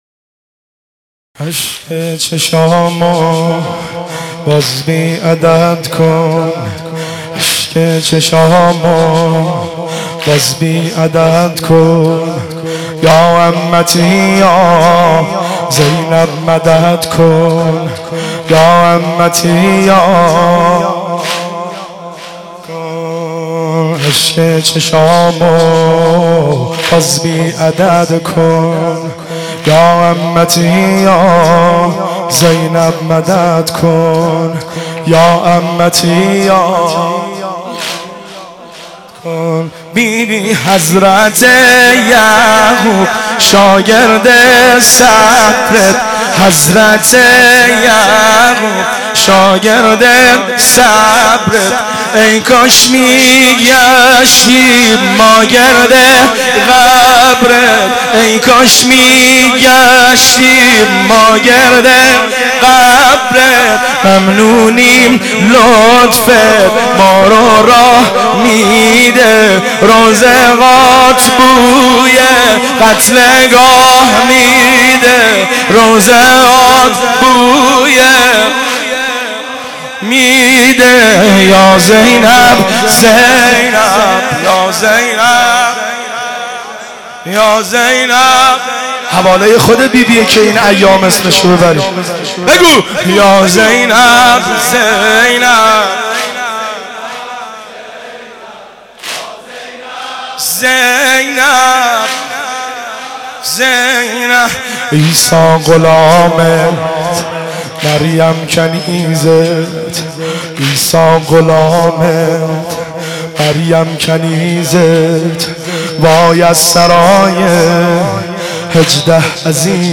مناسبت : شب بیست و دوم رمضان